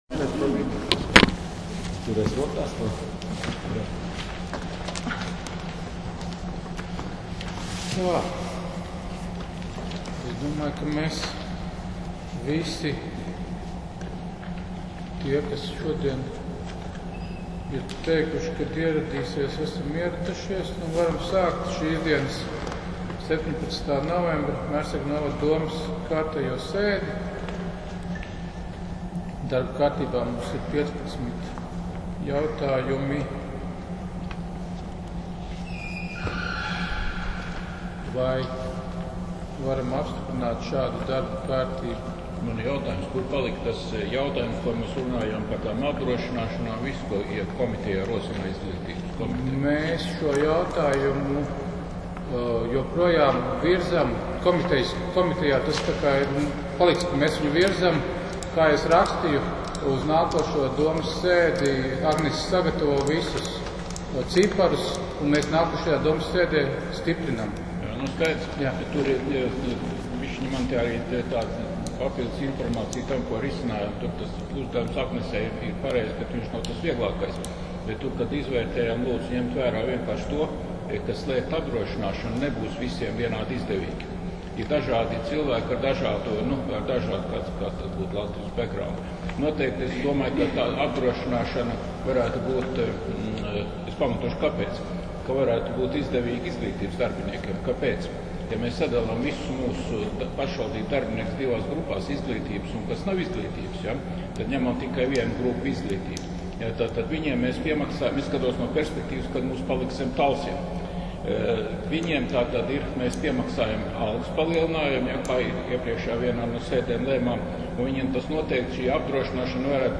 Mērsraga novada domes sēde 17.11.2020.